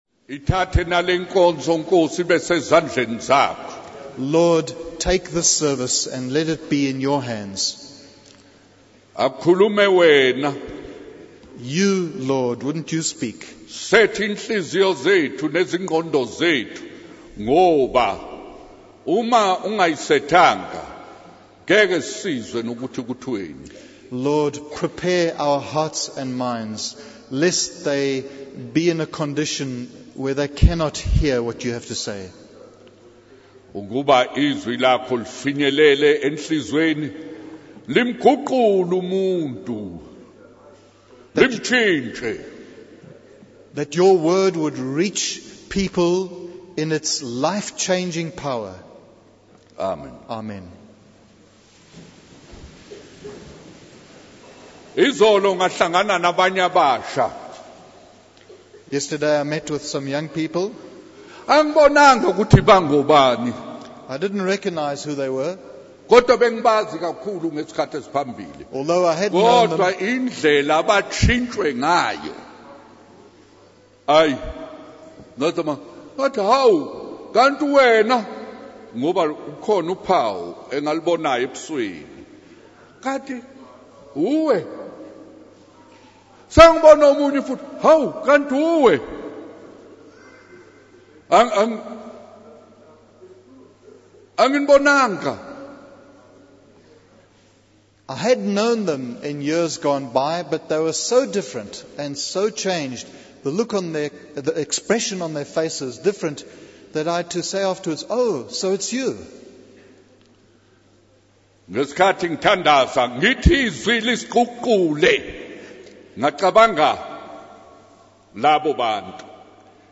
In this sermon, the speaker emphasizes the importance of facing challenges and difficulties in life.